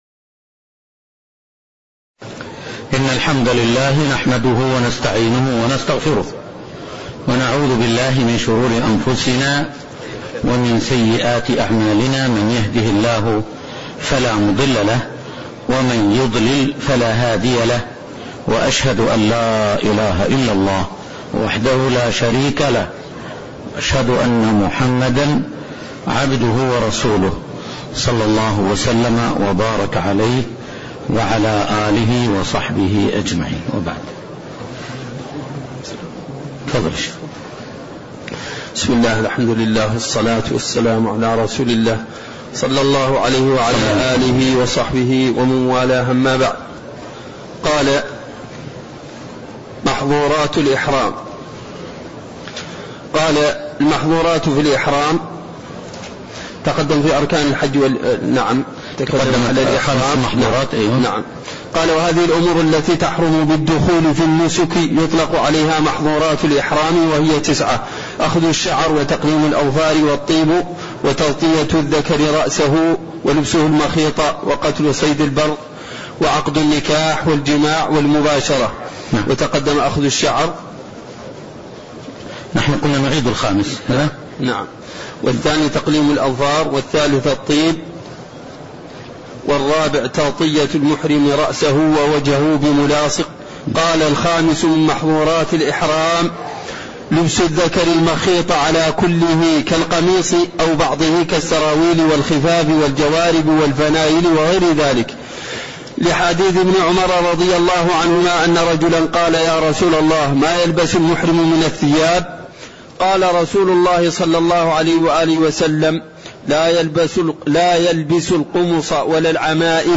تاريخ النشر ٢٥ ذو القعدة ١٤٣٠ هـ المكان: المسجد النبوي الشيخ